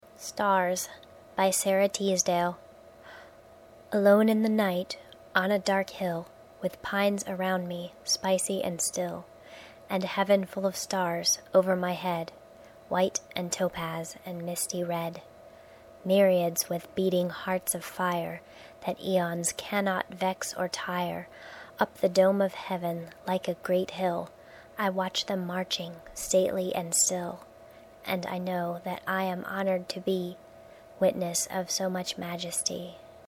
Poem RecitationHear my "presentation" voice as I read the poem "Stars" by Sara Teasdale.